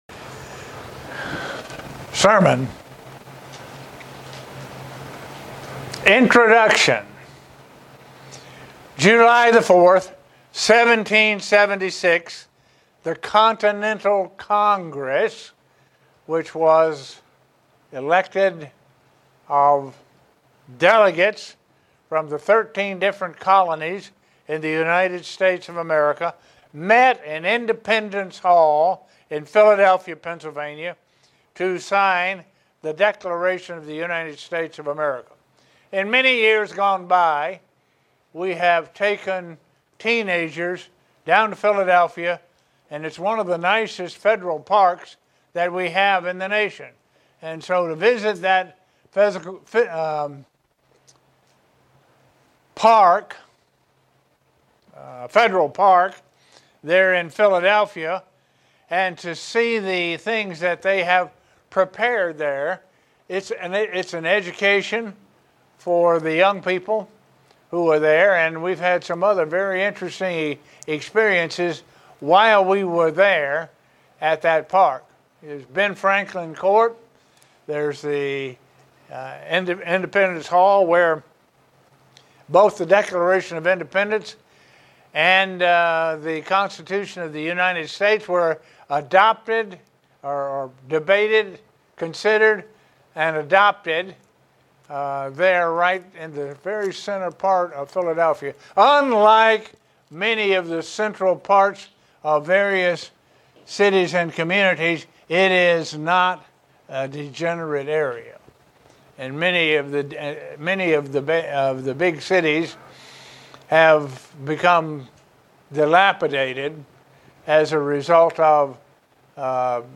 Given in Buffalo, NY
God is creating a nation of His own today. sermon Studying the bible?